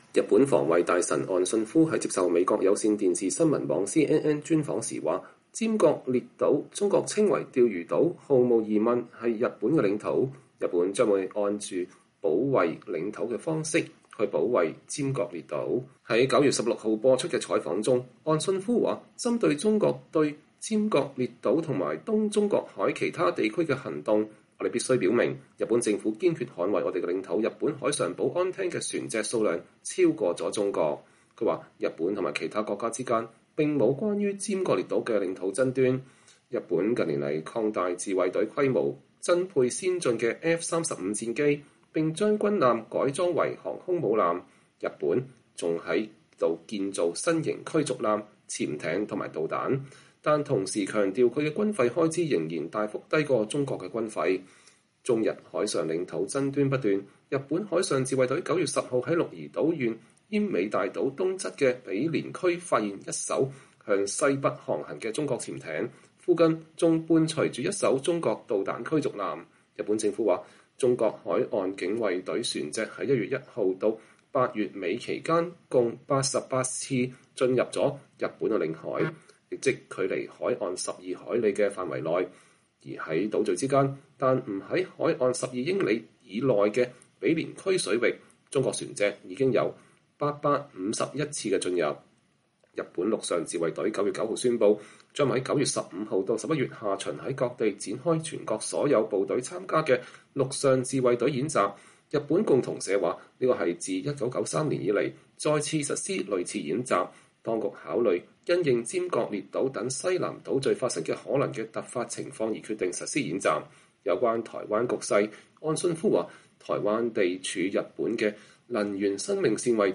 日本防衛大臣岸信夫視察了停靠在日本橫須賀美軍基地的英國航母“伊麗莎白女王”號後對媒體講話。(2021年9月6日)